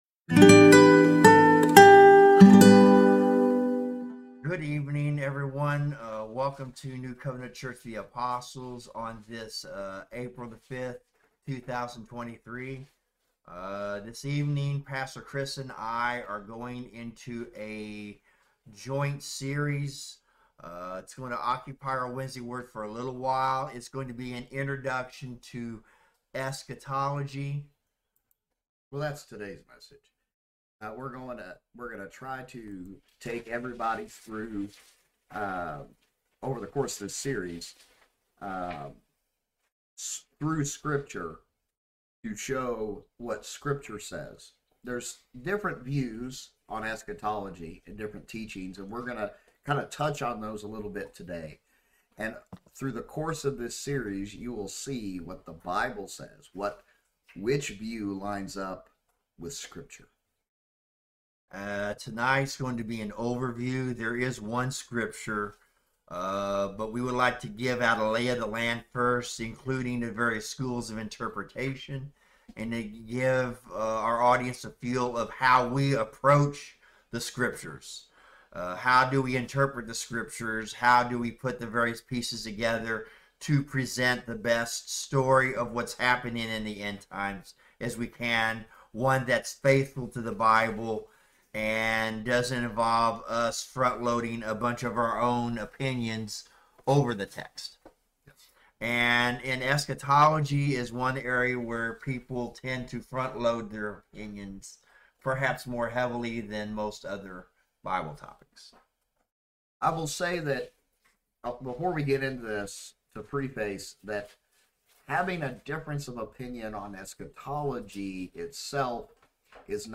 Wednesday Word Bible Study